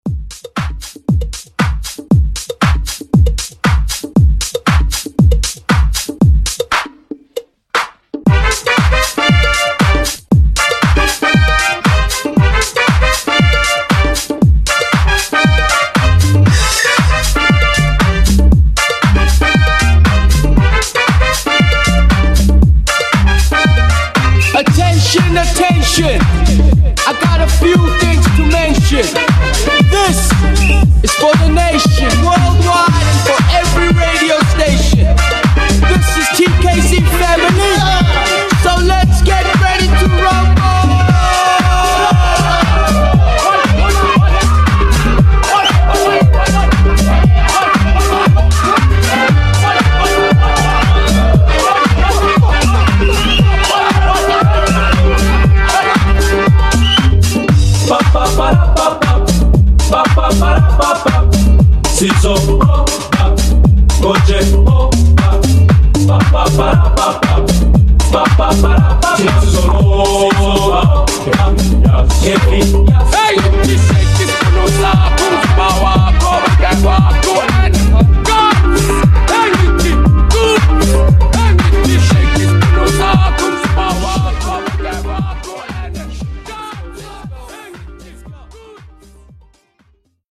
Genres: 90's , AFROBEAT , RE-DRUM
Clean BPM: 117 Time